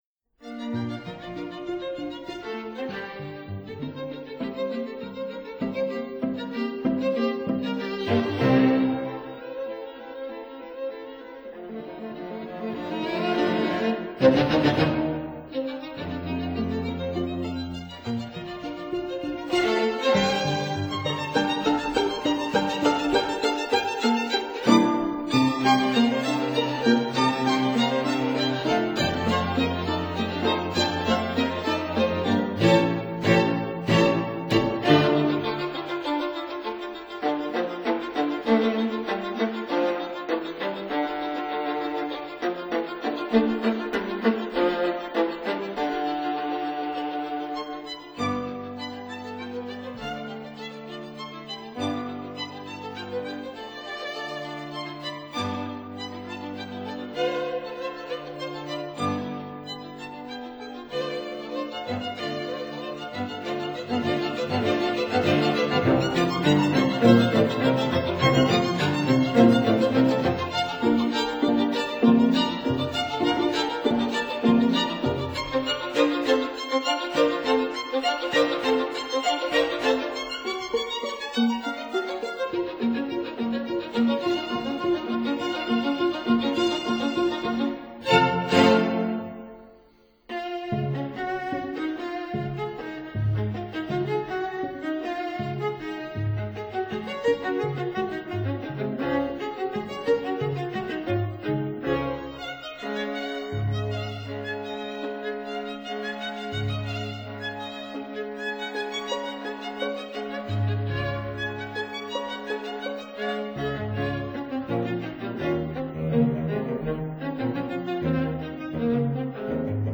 violins
viola
cello